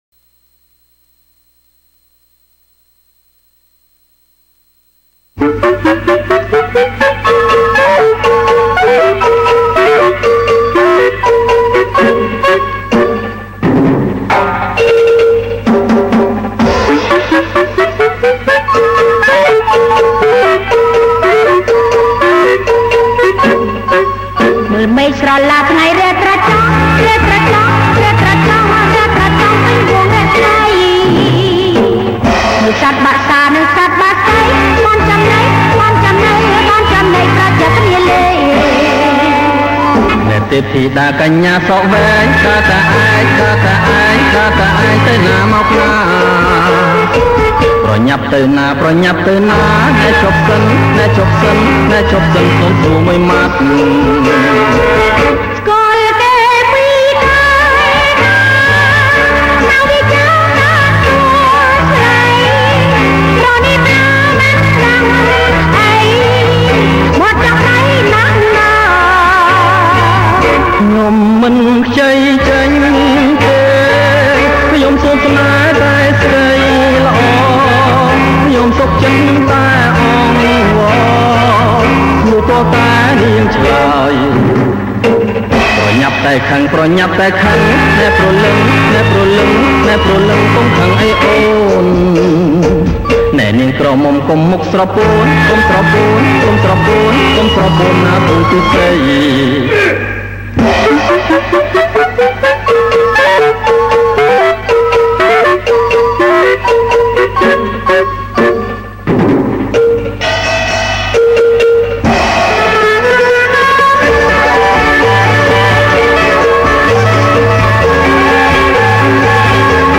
ប្រគំជាចង្វាក់ Cha Cha Cha